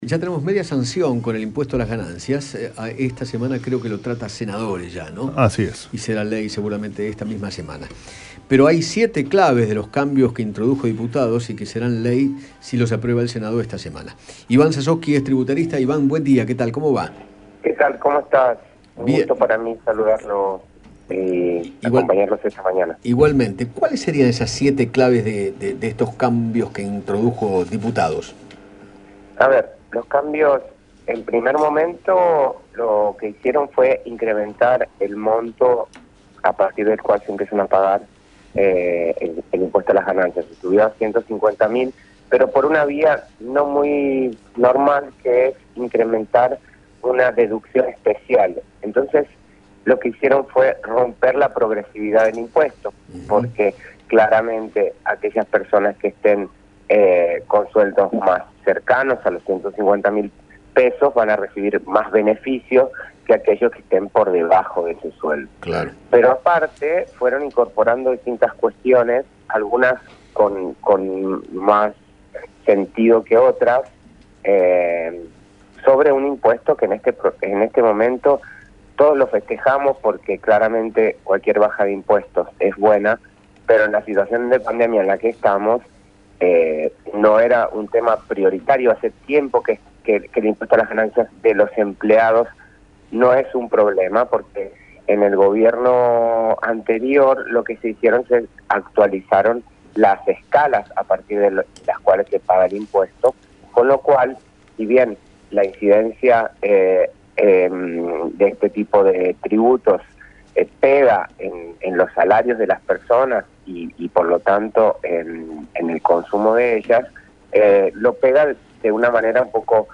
conversó con Eduardo Feinmann